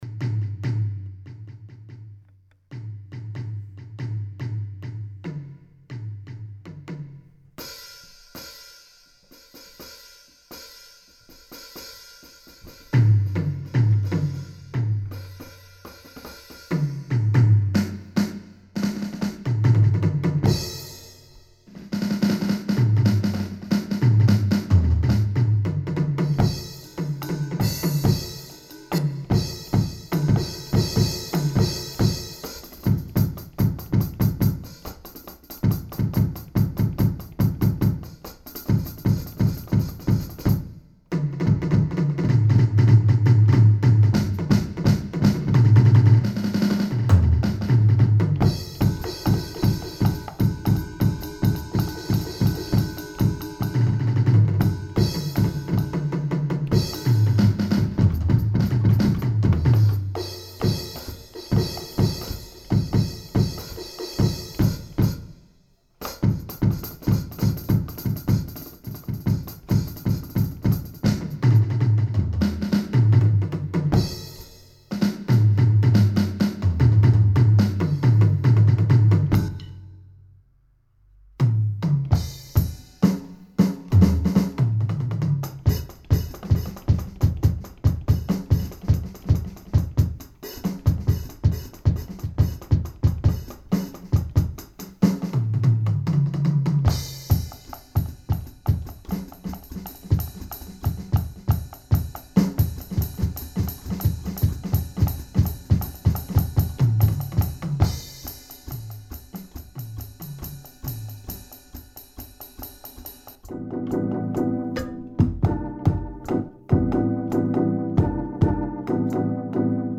Essais percussifs